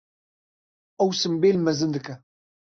Pronounced as (IPA) /sɪmˈbeːl/